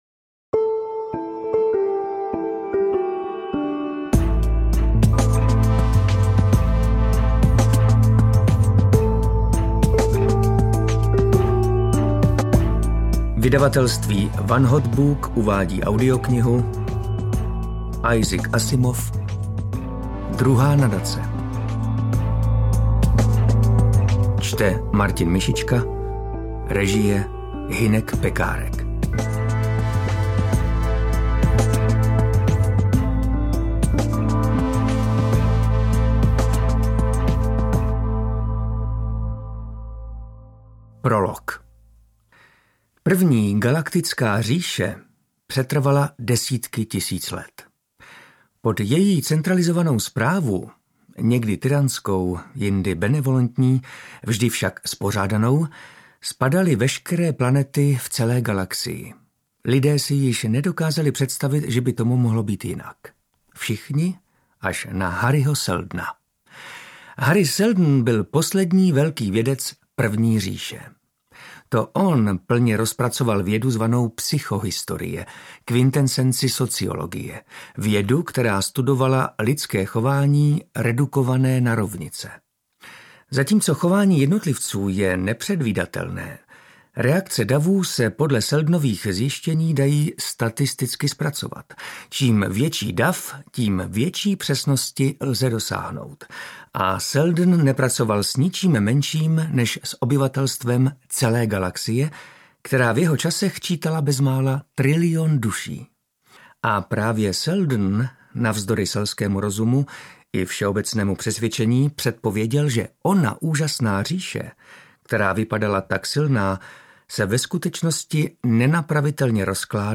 Interpret:  Martin Myšička
AudioKniha ke stažení, 37 x mp3, délka 9 hod. 11 min., velikost 500,0 MB, česky